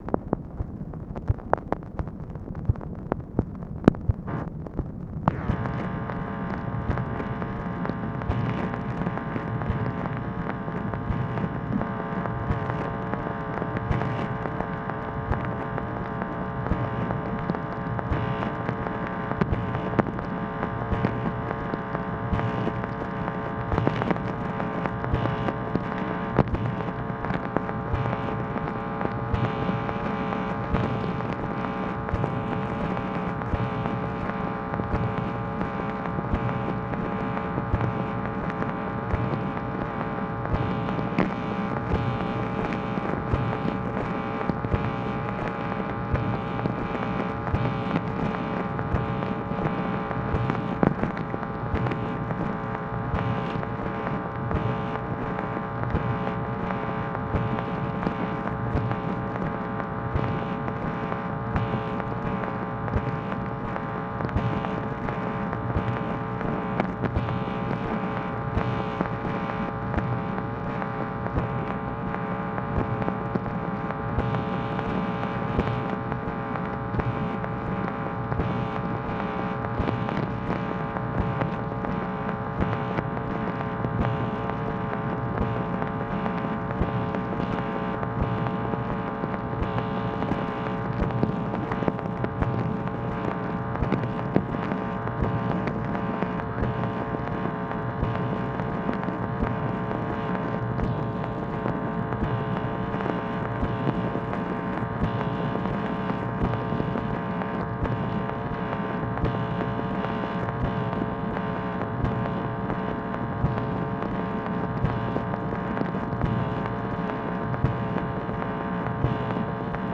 MACHINE NOISE, August 26, 1968
Secret White House Tapes | Lyndon B. Johnson Presidency